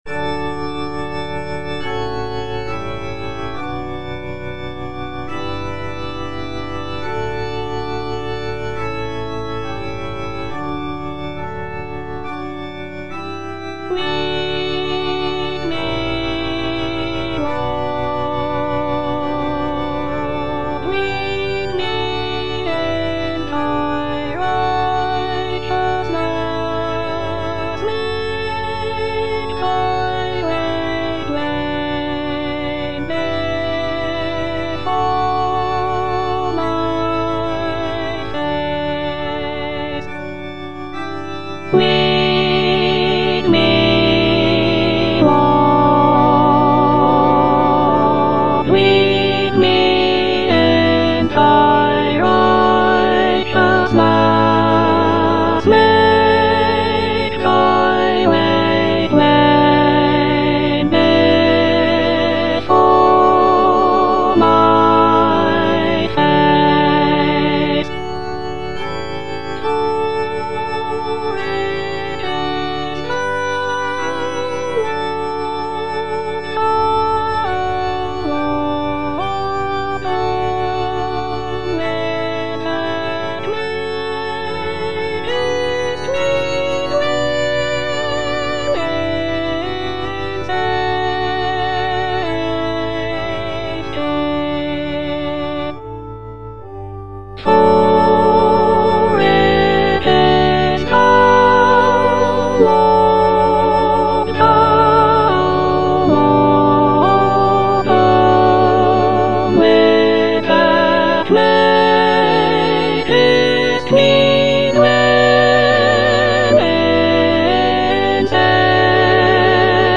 S.S. WESLEY - LEAD ME, LORD Soprano (Emphasised voice and other voices) Ads stop: auto-stop
"Lead me, Lord" is a sacred choral anthem composed by Samuel Sebastian Wesley in the 19th century.
The music is characterized by lush choral textures and expressive dynamics, making it a popular choice for church choirs and worship services.